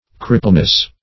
Crippleness \Crip"ple*ness\, n.